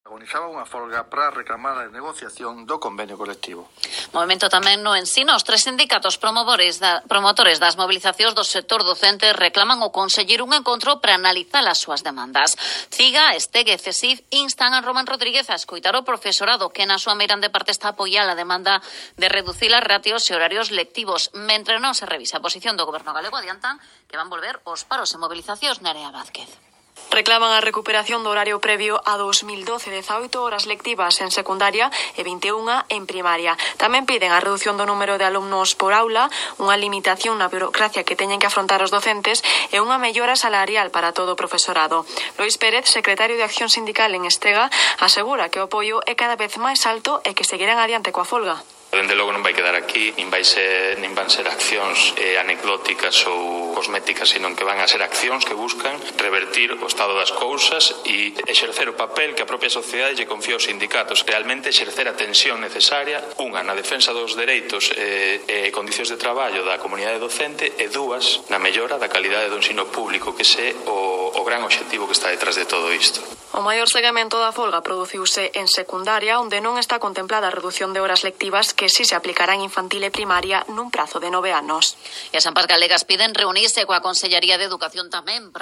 Entrevista en RNE Galicia
entrevista_rne.mp3